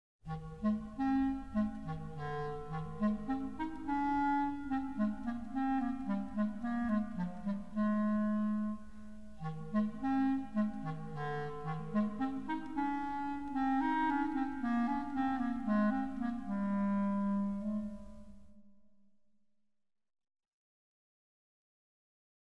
clarinete.mp3